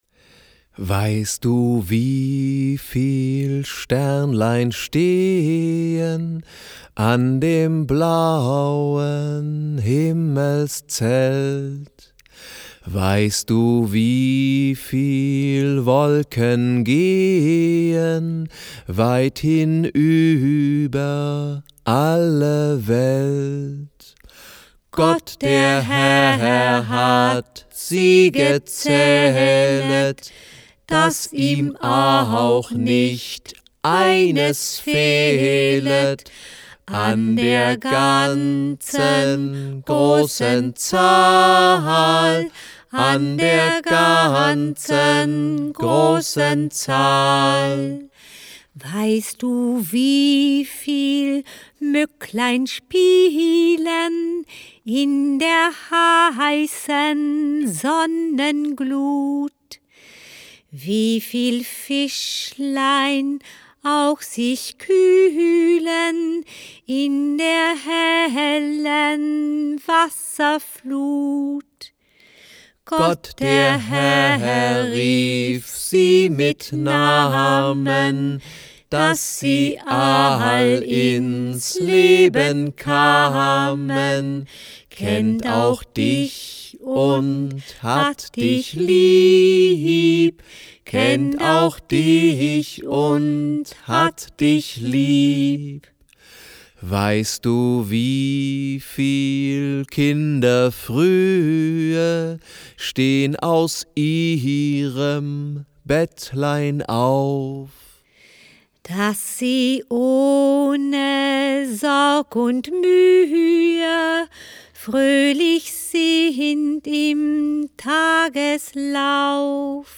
Schlagworte Abendlieder • Aktivierung • Altenarbeit • Alzheimer • Alzheimer Beschäftigung • Alzheimer Geschenk • Alzheimer Geschenkbuch • Alzheimer Musik • Alzheimer Spiele • Bilderbuch • Biographiearbeit • Bücher für alzheimerkranke Menschen • Bücher für Alzheimer Patienten • Bücher für demenzkranke Menschen • Bücher für Demenz Patienten • Dementenarbeit • Demenz • Demenz Beschäftigung • Demenz Geschenk • Demenz Geschenkbuch • Demenz Musik • Demenz Spiele • Gute-Nacht-Lieder • Hörbuch • Liederbuch • Musiktherapie • Soundbuch • Soundchip • Tonleistenbuch • Volkslieder